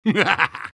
soldier_laughevil03.mp3